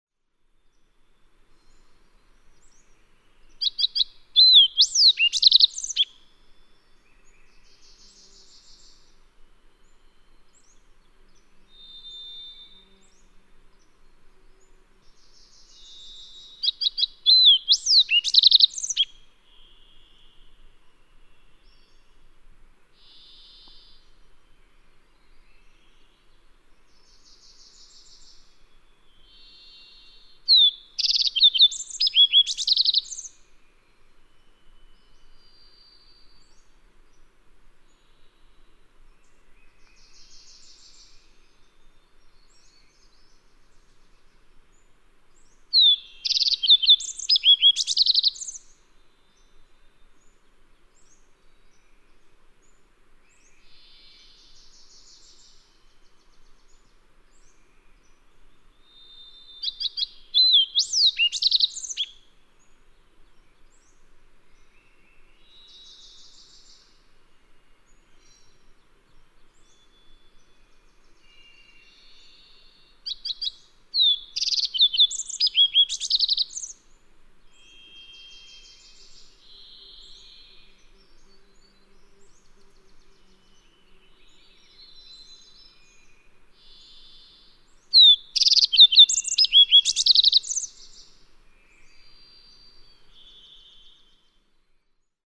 The "sooty" fox sparrow:
592_Fox_Sparrow.mp3